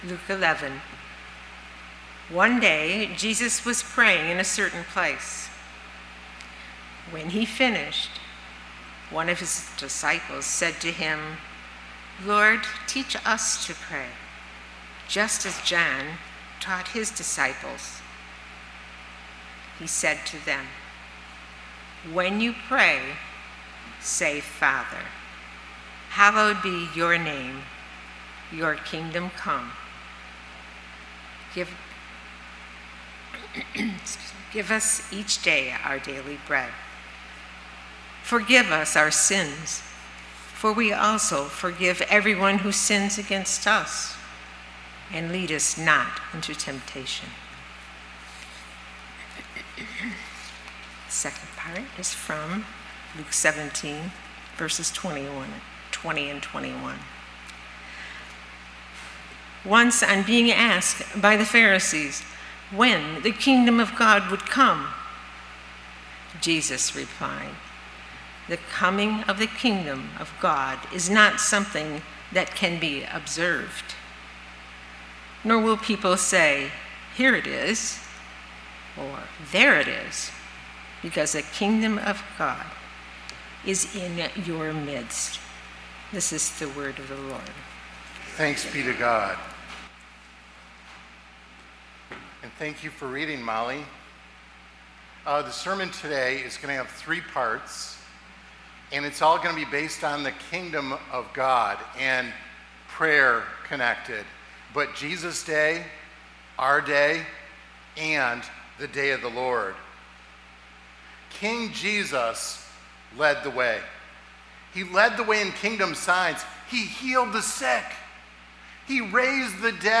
Lakeview Sermon Podcast